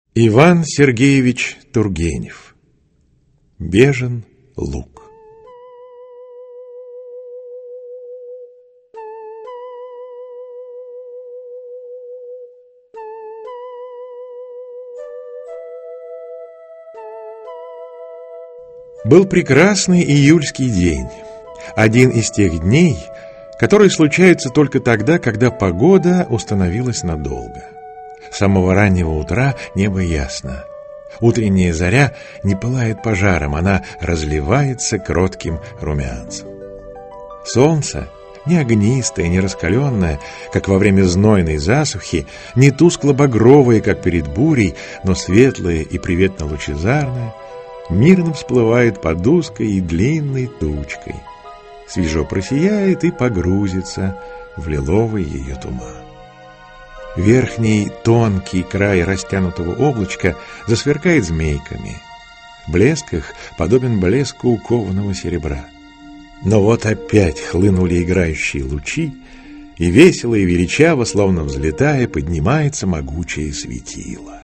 Аудиокнига Бежин луг | Библиотека аудиокниг
Aудиокнига Бежин луг Автор Иван Тургенев Читает аудиокнигу Евгений Киндинов.